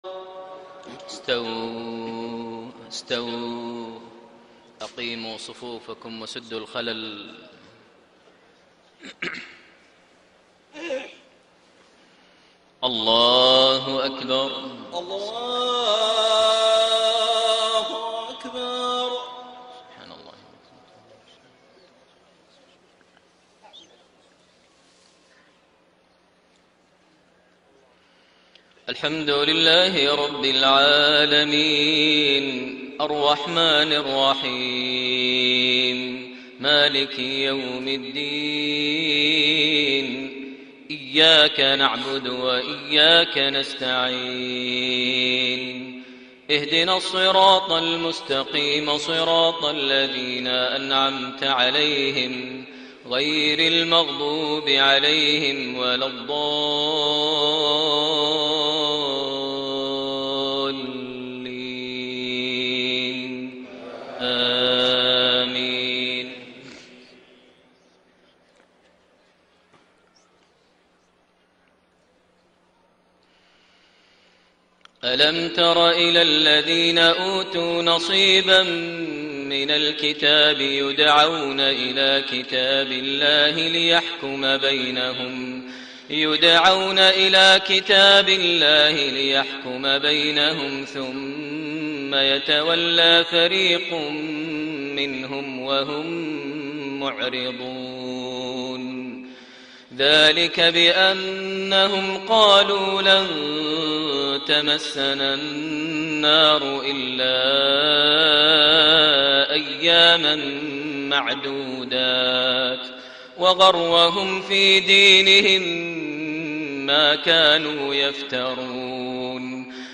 Maghrib prayer from Surah Aal-i-Imraan > 1433 H > Prayers - Maher Almuaiqly Recitations